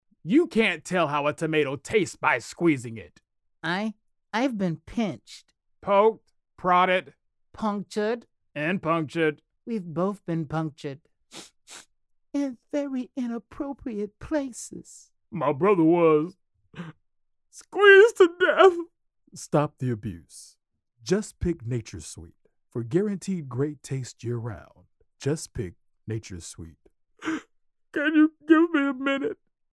Demo
Young Adult, Adult, Mature Adult
Has Own Studio
bro
comedy